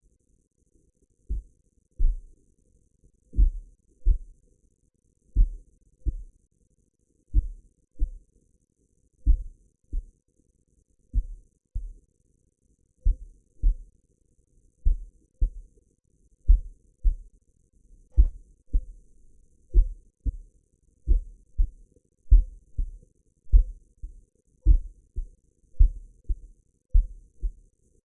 生物的声音 " 龙的叫声
描述：怪物或龙的呐喊
标签： 尖叫等
声道立体声